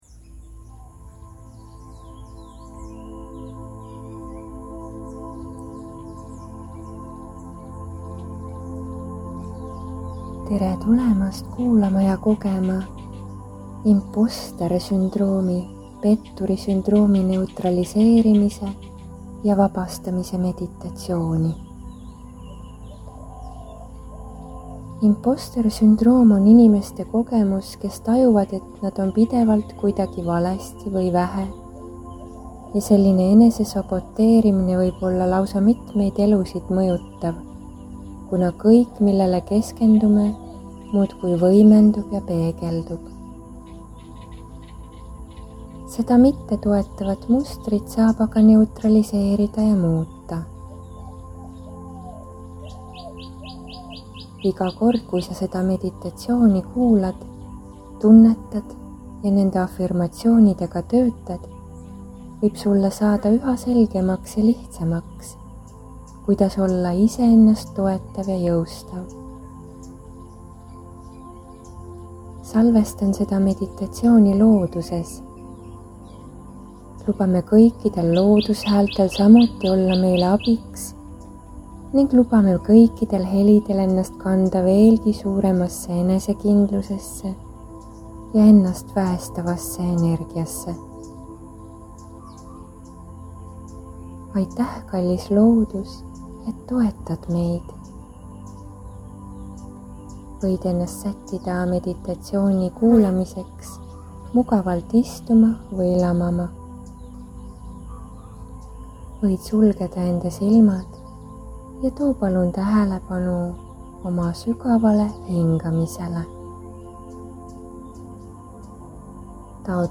Imposter sündroomi tervenduse meditatsioon